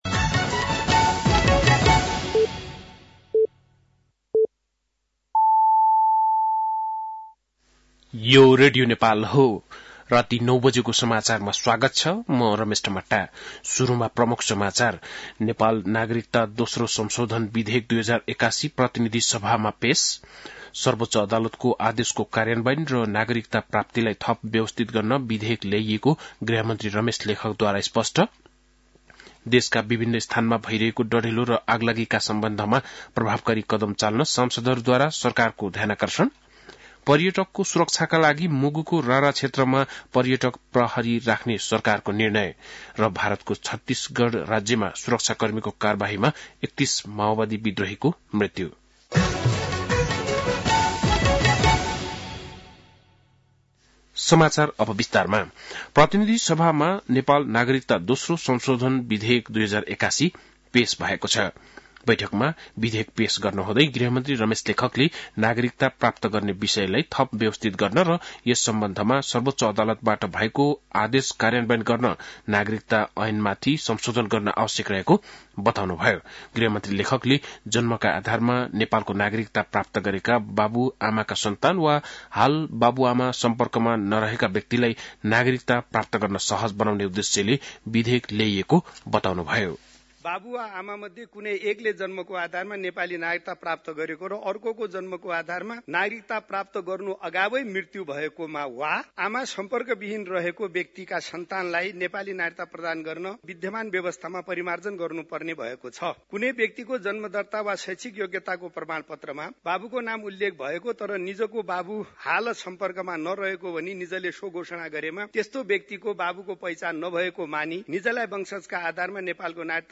बेलुकी ९ बजेको नेपाली समाचार : २८ माघ , २०८१
9-pm-nepali-news10-27.mp3